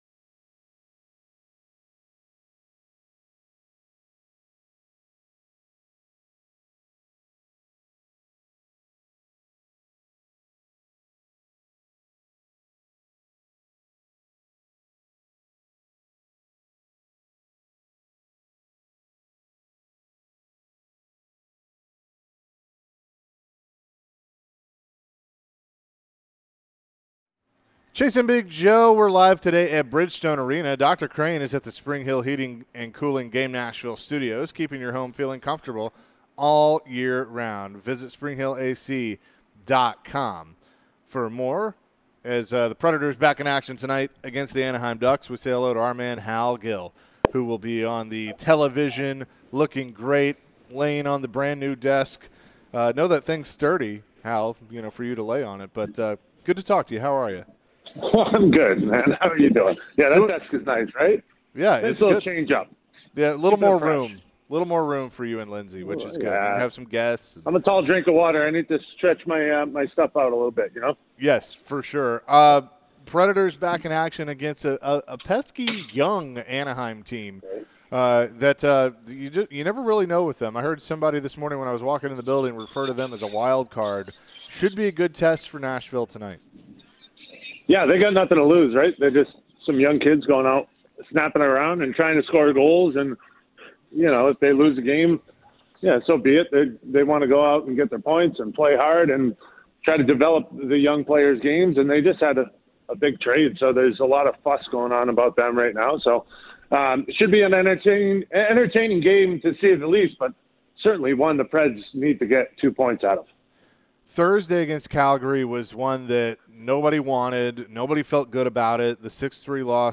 Preds TV and radio analyst, Hal Gill, joins the show to talk about the Preds and their game tonight. Are the Nashville Predators a playoff team?